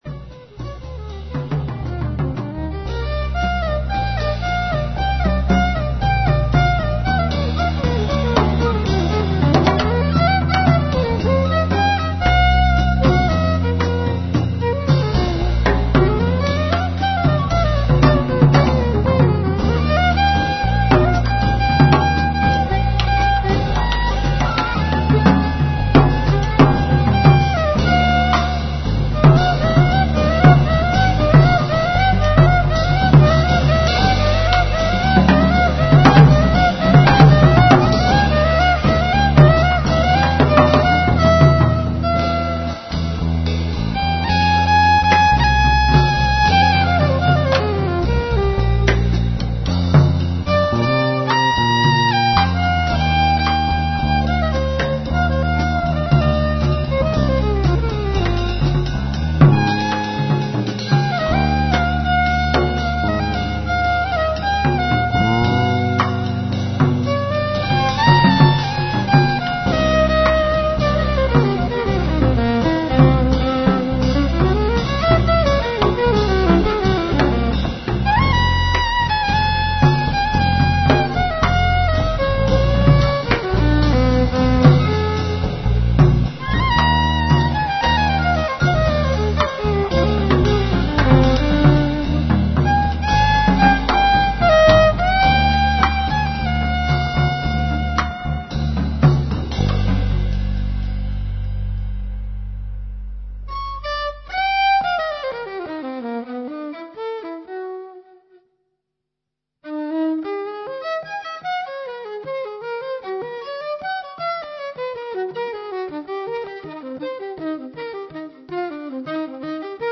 violin, percussion, guitar, fluguelhorn, vocals
elettric bass
drums, surdo